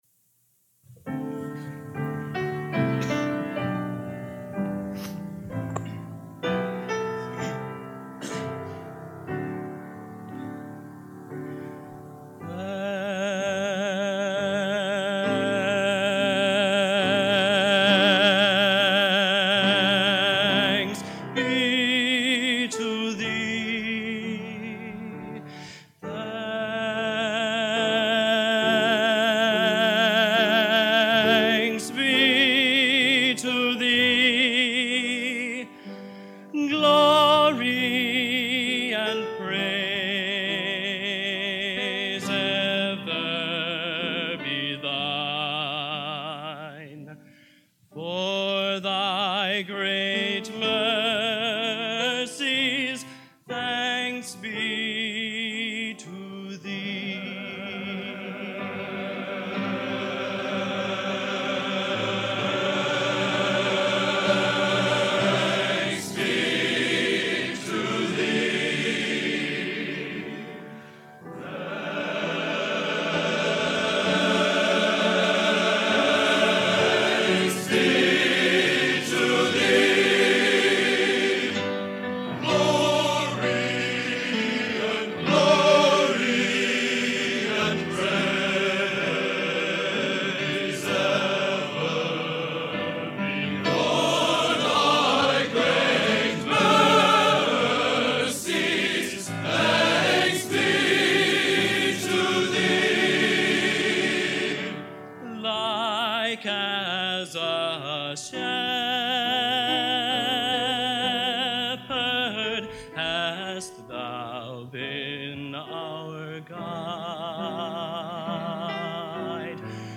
Genre: Modern Sacred | Type: End of Season